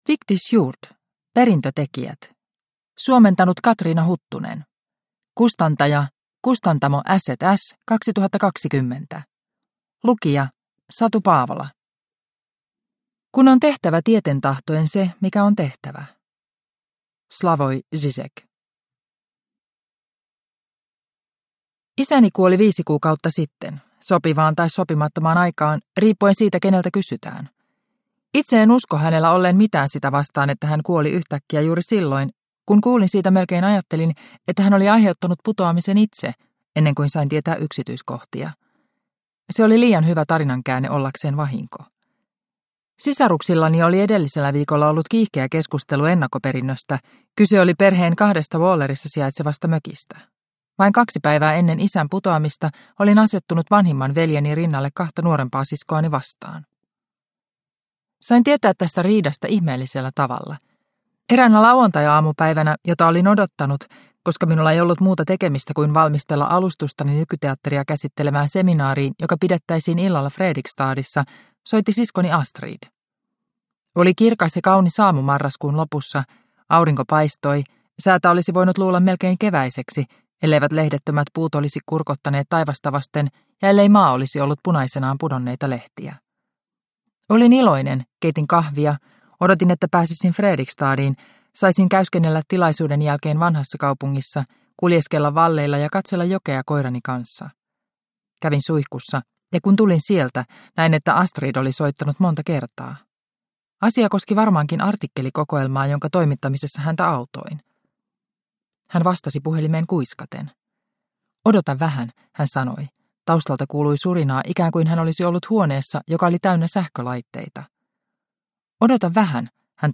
Perintötekijät – Ljudbok – Laddas ner